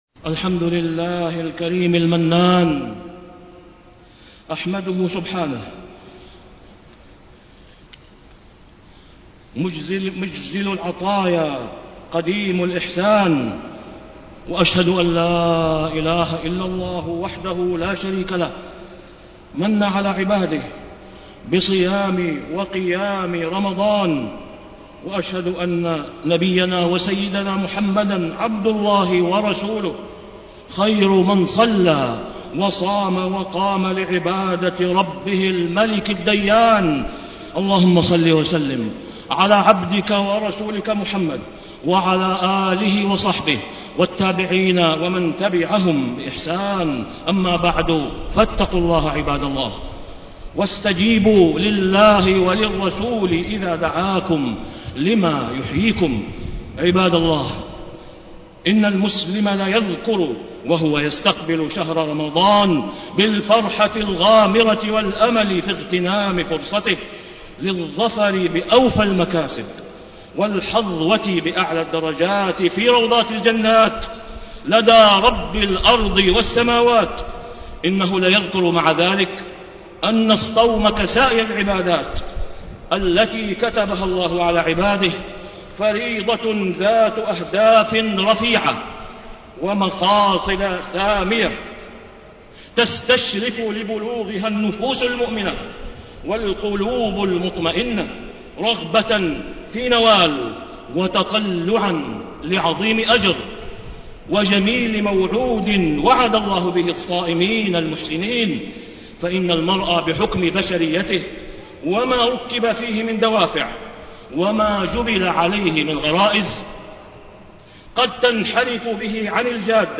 تاريخ النشر ٢٩ رمضان ١٤٣٥ هـ المكان: المسجد الحرام الشيخ: فضيلة الشيخ د. أسامة بن عبدالله خياط فضيلة الشيخ د. أسامة بن عبدالله خياط الصيام أهداف ومقاصد